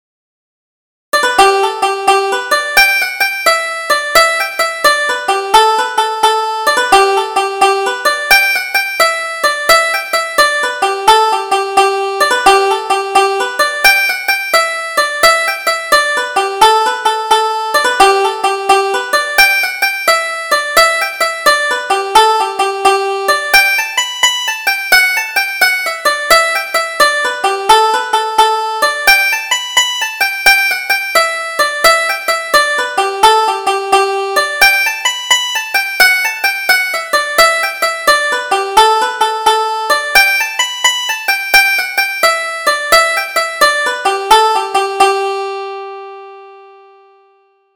Double Jig: The Red Rose